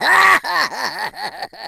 File:King Boo Laugh.oga
Voice clip from Luigi's Mansion
King_Boo_Laugh.oga.mp3